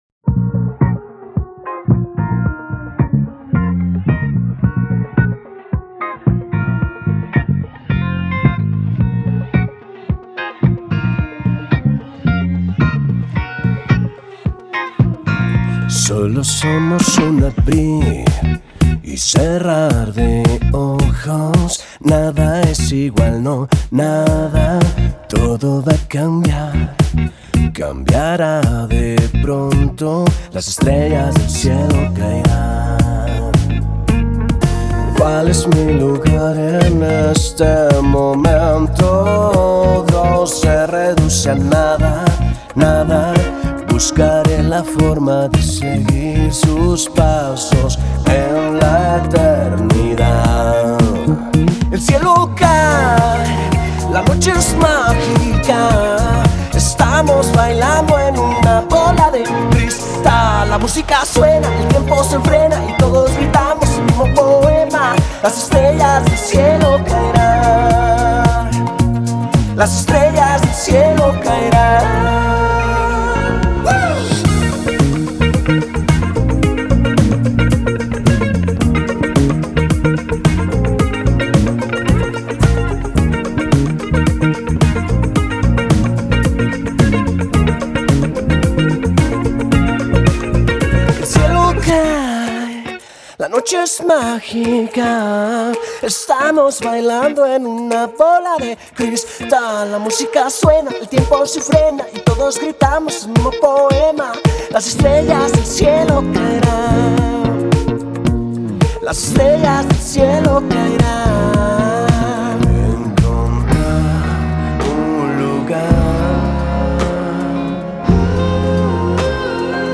una mezcla de funk, indie y esencia disco que